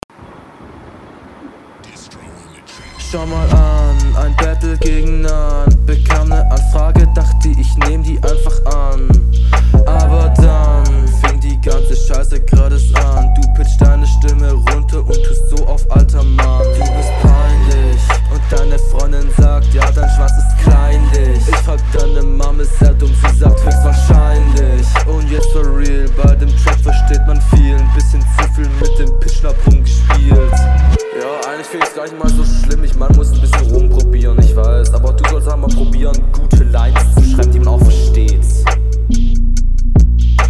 Sagt mir direkt etwas mehr zu. Flowlich schießt du auch nicht über das Ziel hinaus, …